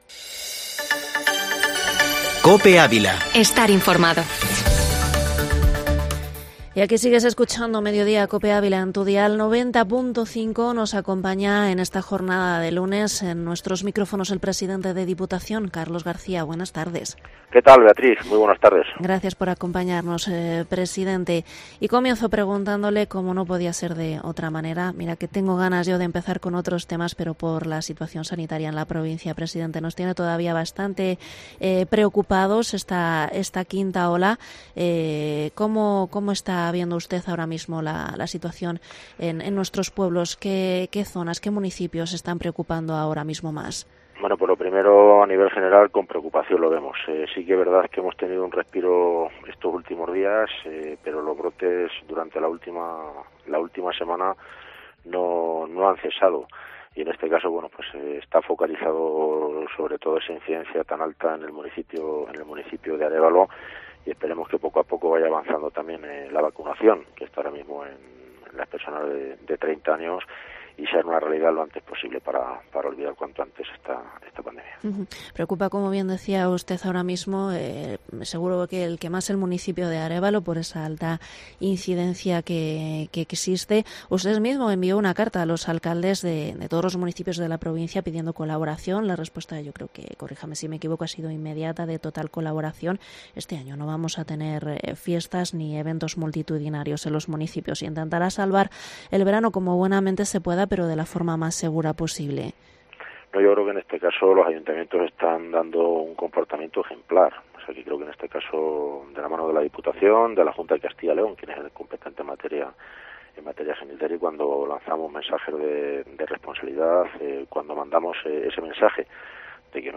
Entrevista a Carlos García, presidente Diputación de Ávila en Mediodía Cope 19/07/2021